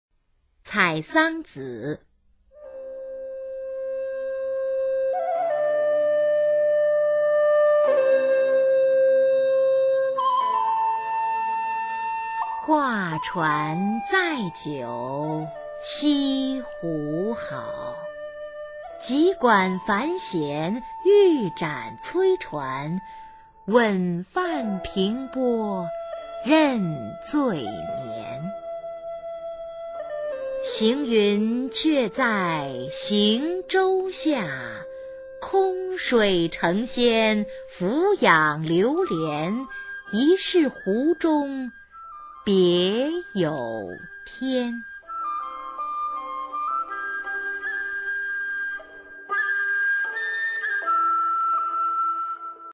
[宋代诗词朗诵]欧阳修-采桑子 古诗词诵读